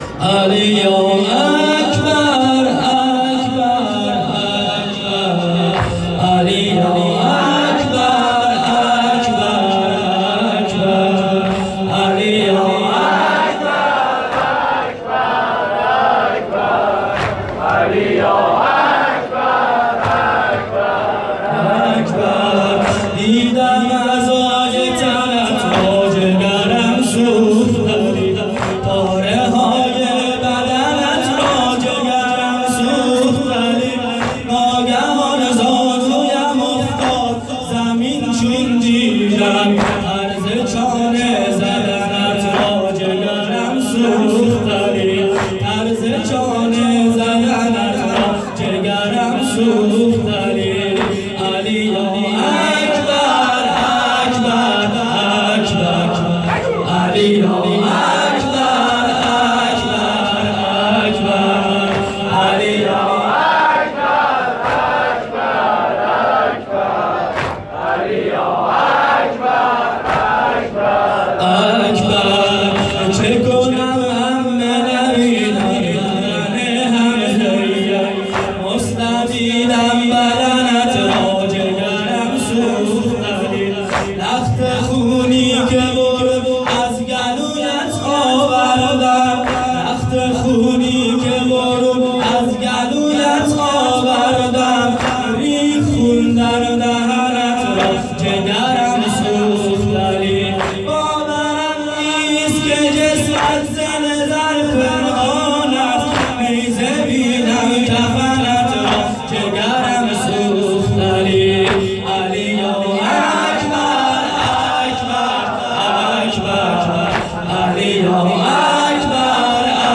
شب هشتم محرم ۹۷ هیئت میثاق الحسین(ع)سیستان